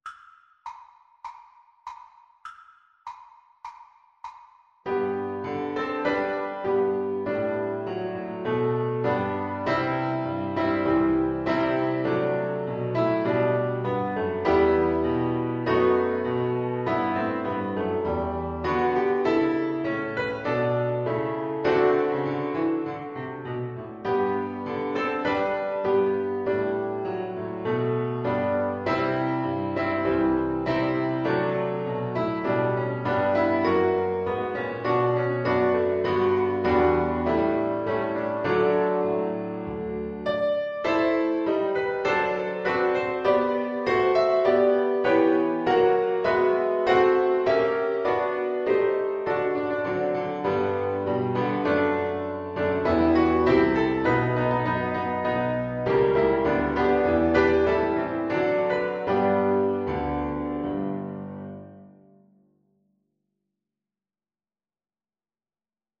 4/4 (View more 4/4 Music)
G major (Sounding Pitch) (View more G major Music for Flute )
irish_nat_anth_FL_kar1.mp3